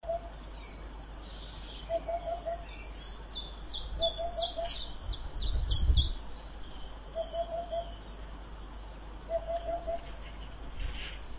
Hoopoe, Upupa epops
Ziņotāja saglabāts vietas nosaukumsGauja
StatusVoice, calls heard